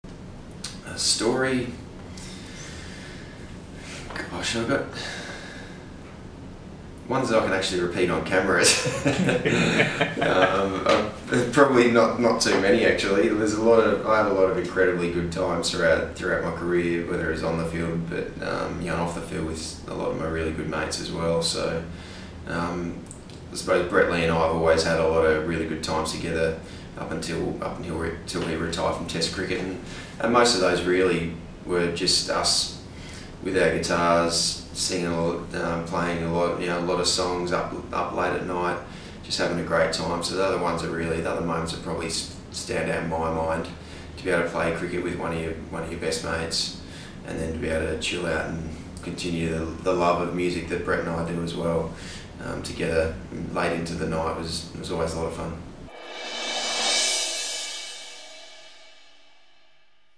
Shane Watson talks about his friendship with teammate Brett Lee.